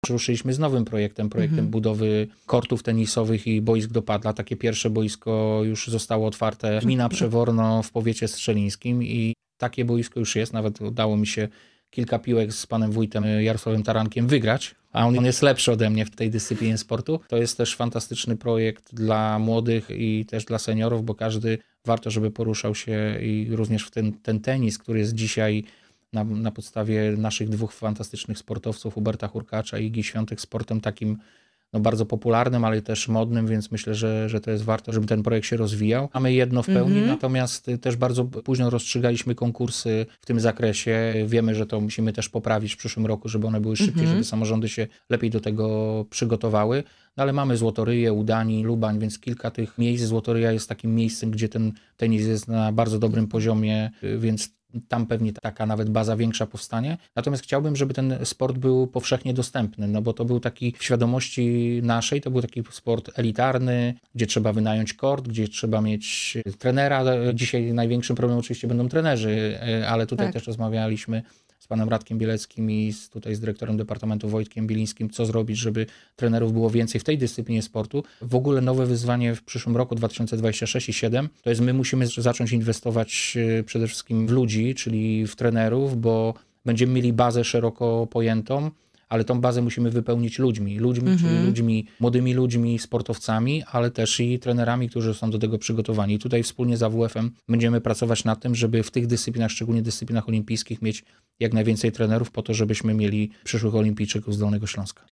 Mówi wicemarszałek Wojciech Bochnak.